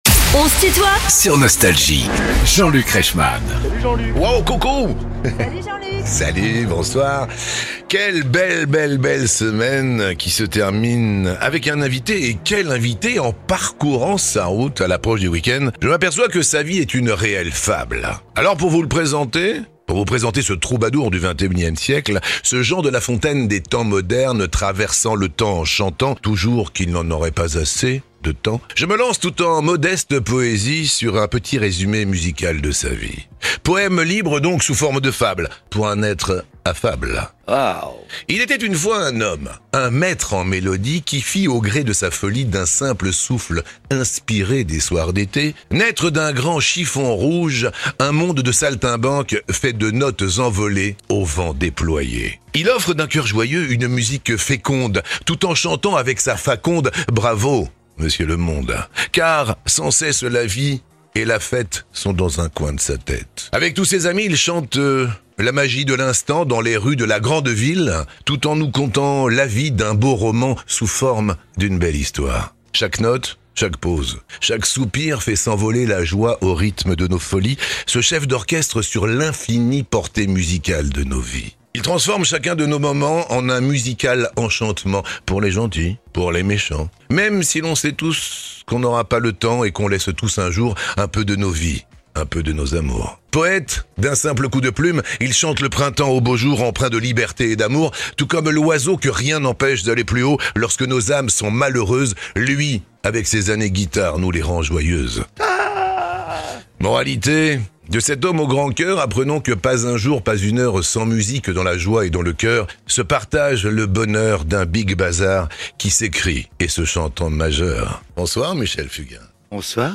Michel Fugain, l'icône de la chanson française, est l’invité de "On se tutoie ?..." avec Jean-Luc Reichmann pour présenter son dernier album La Vie, l’amour, etc… et revenir sur plus de 60 ans de carrière.